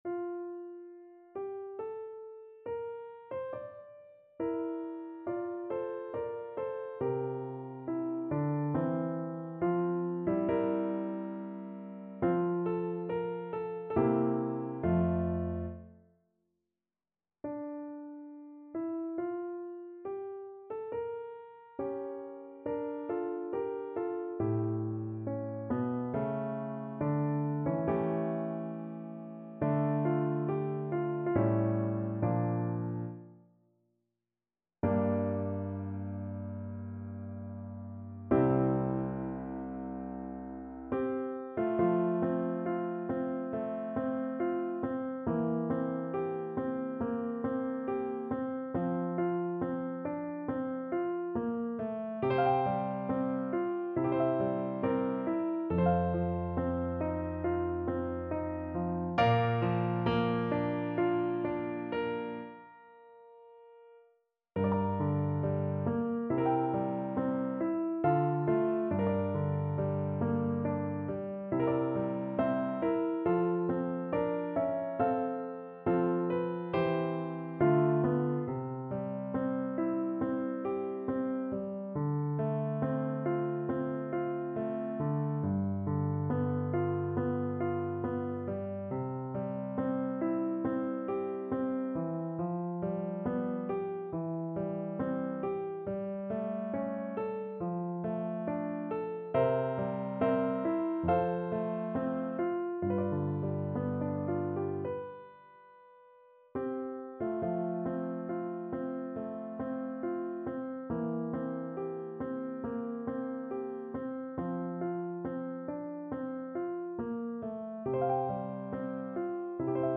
4/4 (View more 4/4 Music)
Larghetto (=80) =69